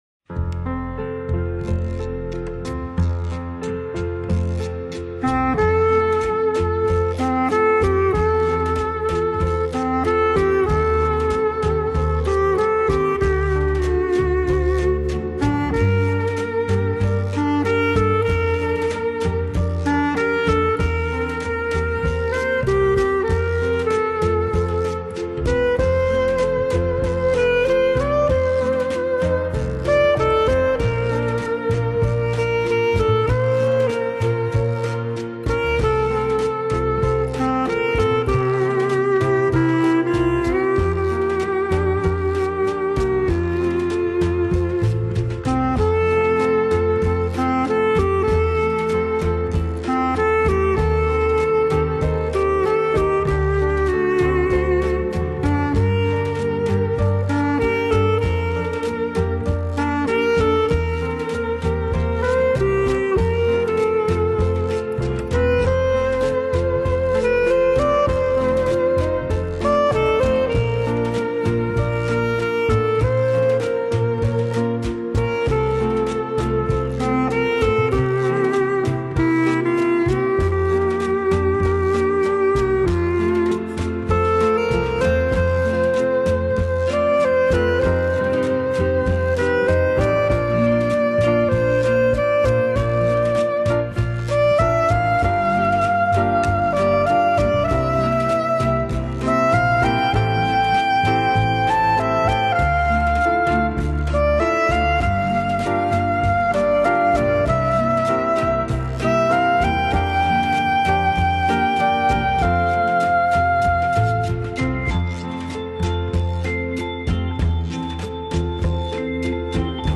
八十年代的輕音樂令人懷念，把浪漫延续下去，让生活更醉人...
风格：Easy Listening